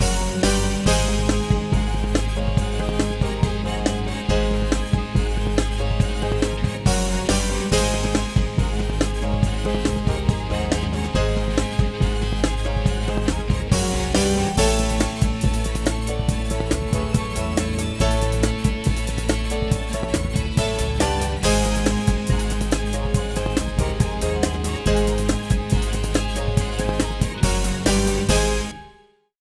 Bright, uptempo. Drums, piano, synth.